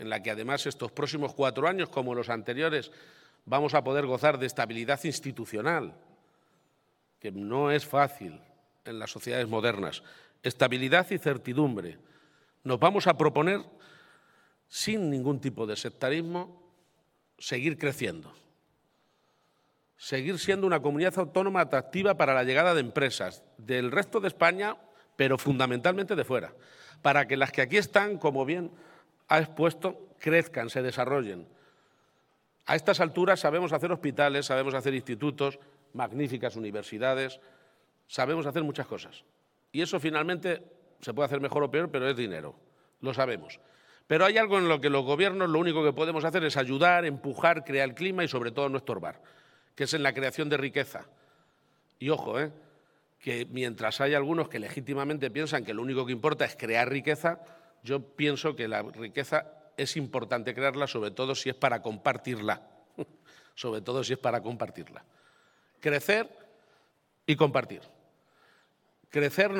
Crecer y compartir" ha señalado esta tarde el presidente de Castilla-La Mancha en su toma de posesión. egapagetomaposesion080723_crecer_y_compartir.mp3 Descargar: Descargar